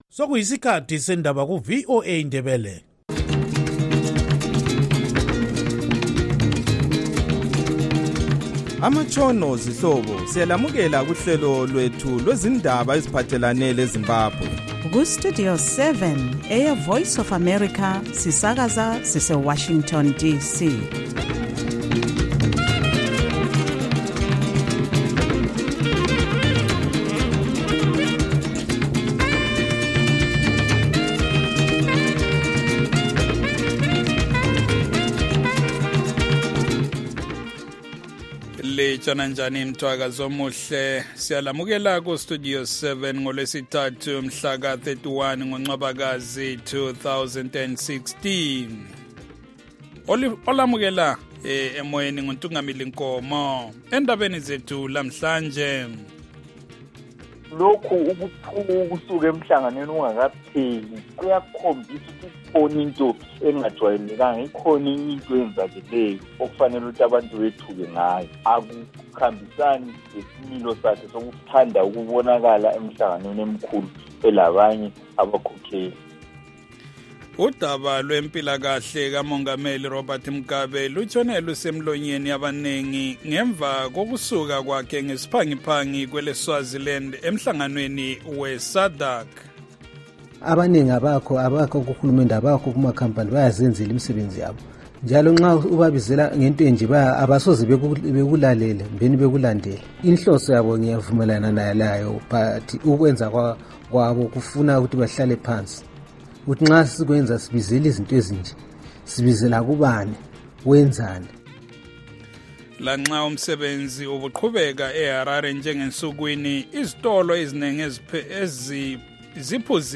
Studio 7 has been providing Zimbabwe with objective, reliable and balanced radio news broadcasts since 2003 and has become a highly valued alternative point of reference on the airwaves for many thousands of Zimbabweans. Studio 7 covers politics, civil society, the economy, health, sports, music, the arts and other aspects of life in Zimbabwe.